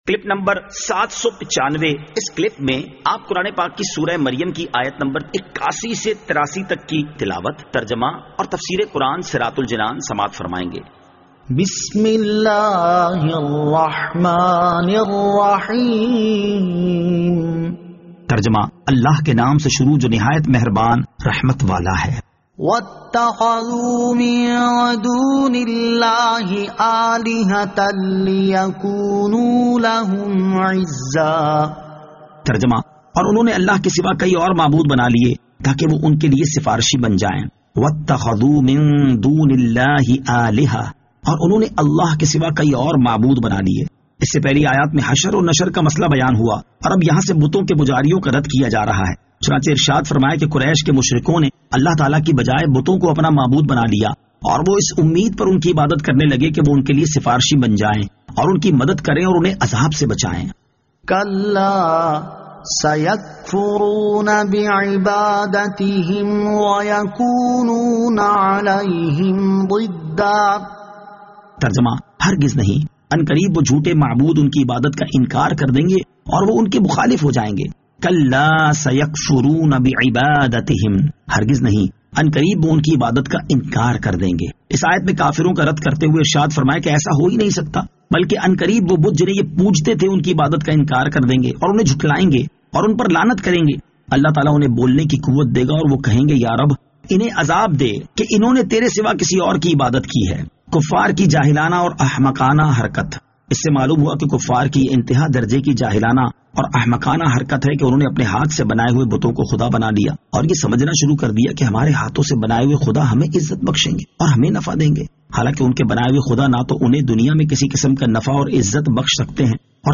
Surah Maryam Ayat 81 To 83 Tilawat , Tarjama , Tafseer